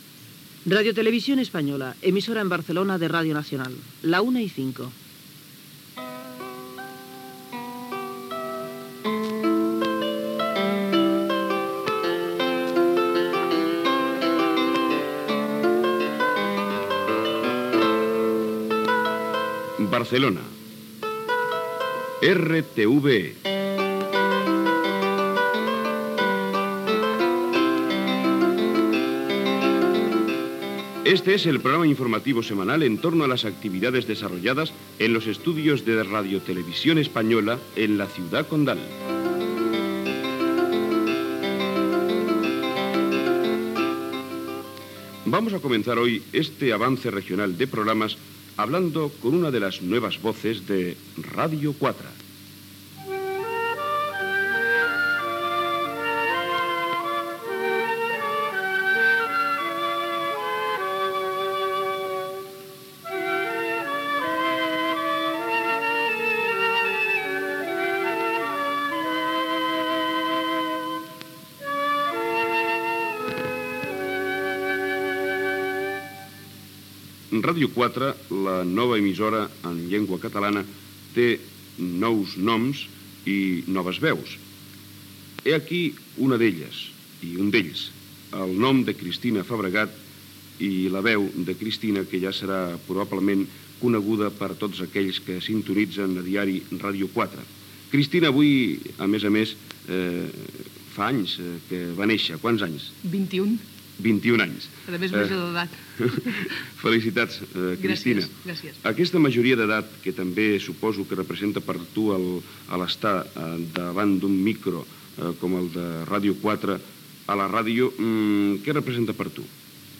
Identificació de l'emissora, hora, careta del programa, sintonia de Ràdio 4 i entrevista
Divulgació